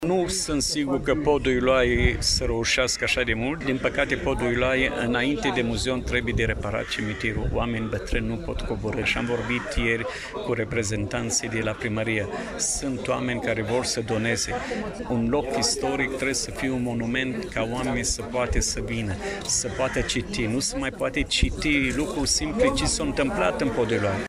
La Cimitirul Evreiesc, a avut loc o manifestare în cadrul căreia au fost rememorate evenimentele triste petrecute în urmă cu 83 de ani, dar și un moment simbolic de recunoaştere a meritelor deosebite ale comunităţii evreieşti în dezvoltarea Iaşiului.